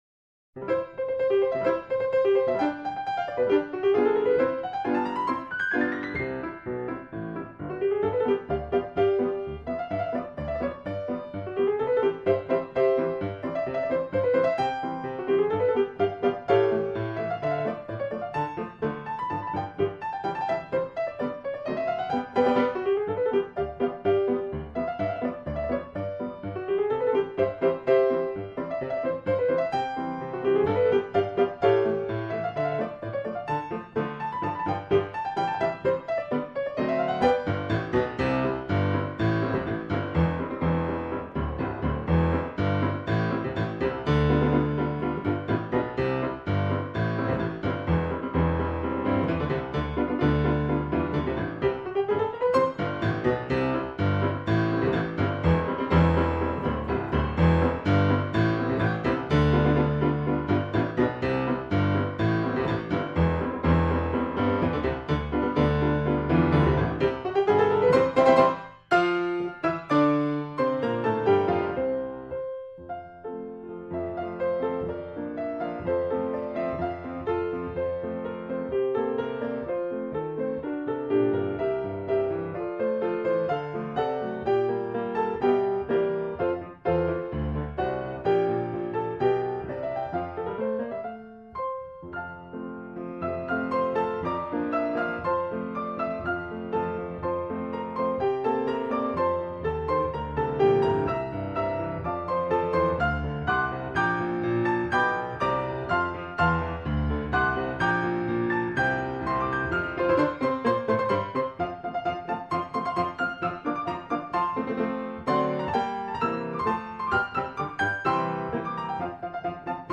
คำสำคัญ : เปียโน, มาร์ชราชวัลลภ, เพลงราชนิพนธ์